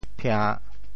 “聘”字用潮州话怎么说？
phia~3.mp3